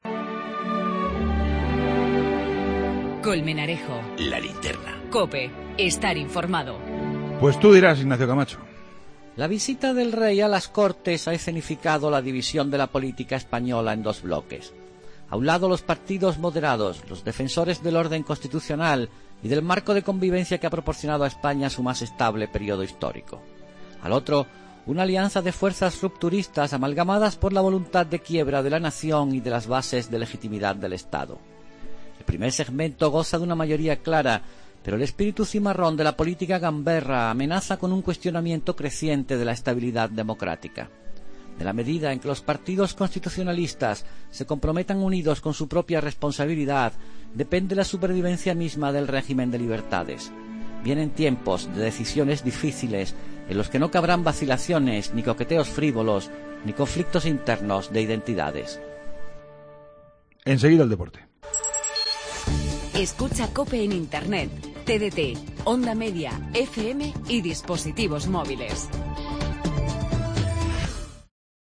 El comentario de Ignacio Camacho en 'La Linterna' sobre la Apertura Solemnse de la XII en la que el Rey ha valorado la “generosidad” que ha permitido que por fin haya Gobierno